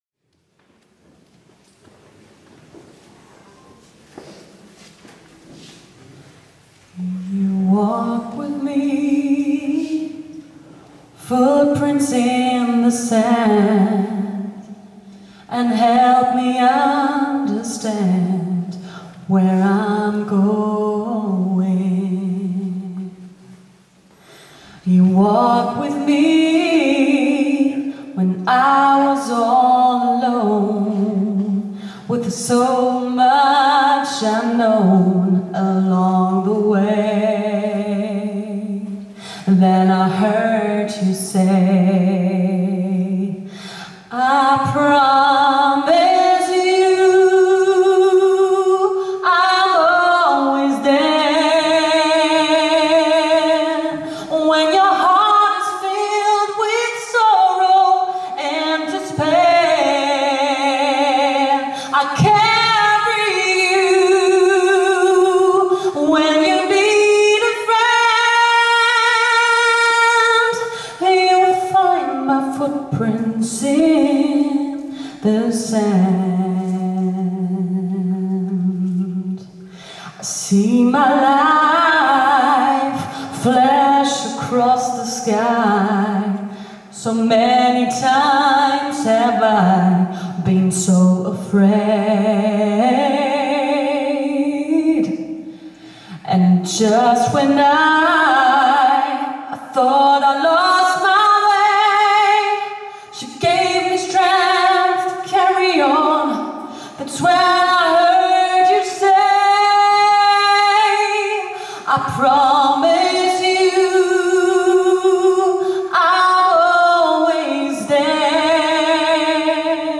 romantic wedding songs
live Beautiful Disaster